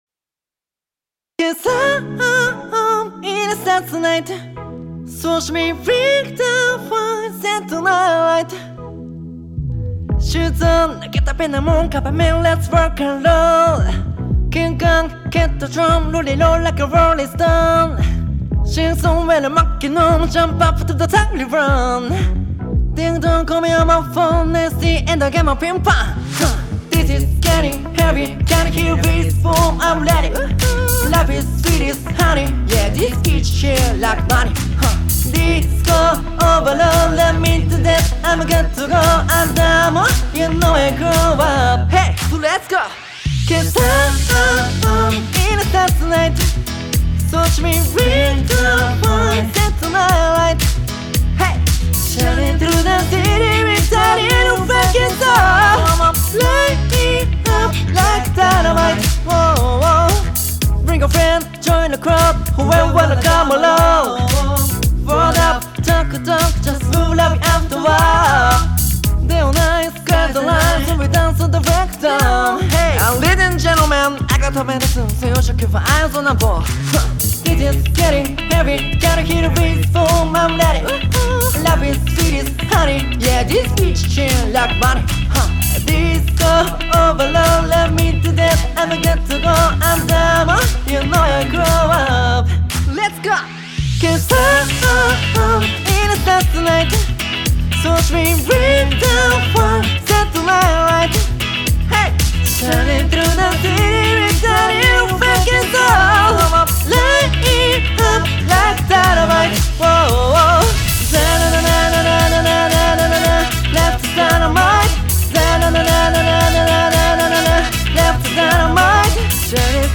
Sample Mix (Voacl Mix)